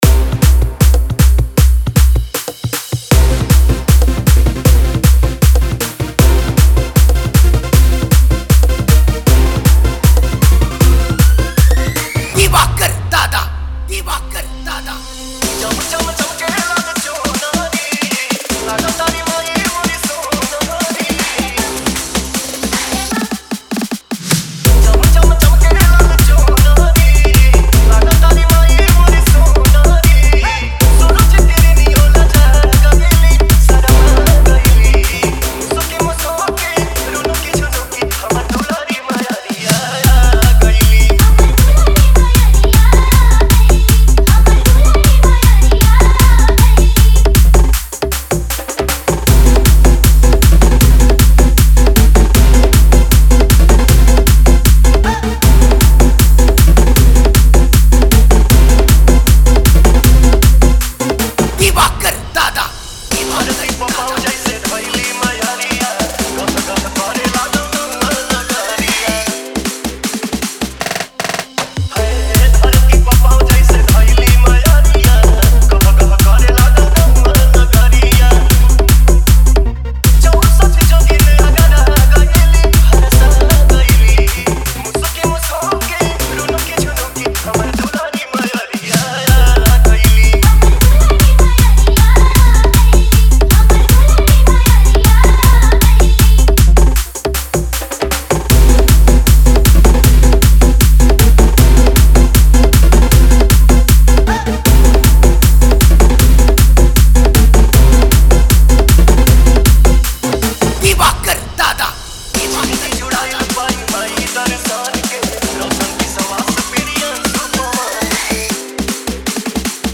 Navratri Dj Song